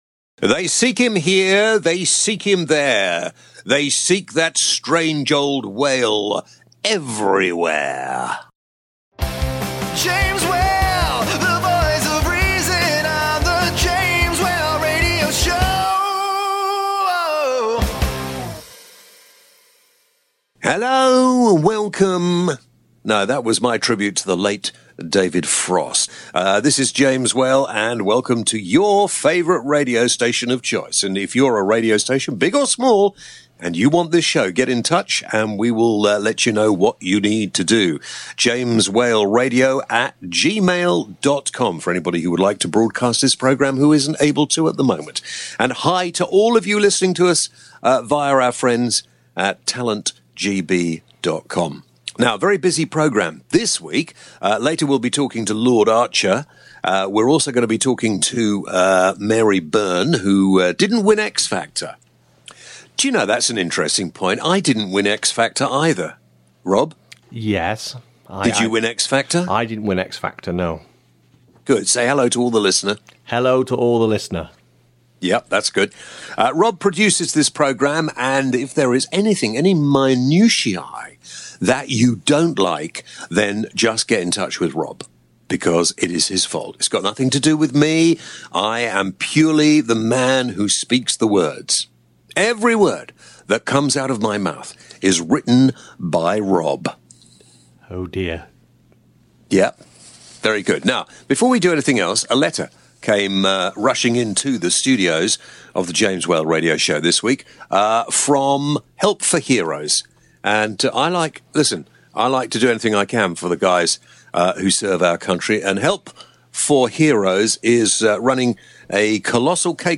James Whale Radio Show – Ep.27 - Guest Jeffrey Archer and Mary Byrne (X-Factor)